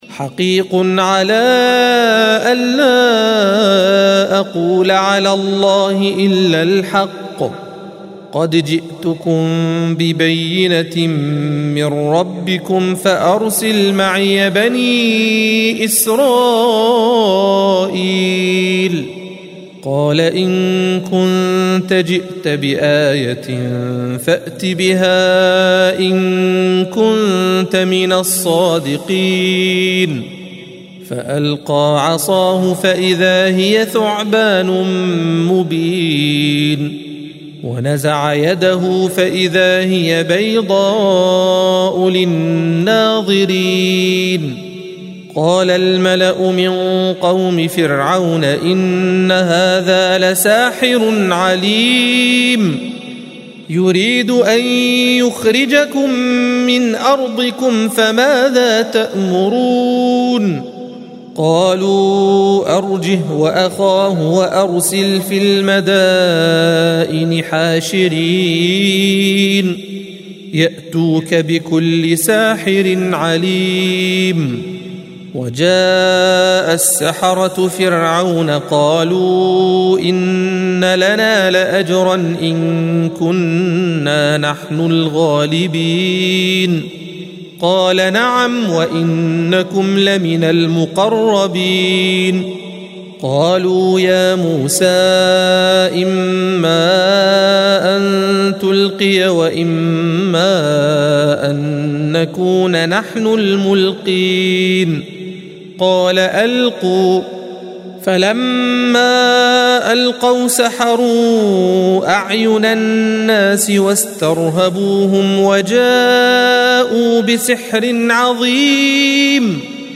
الصفحة 164 - القارئ